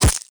strike5.wav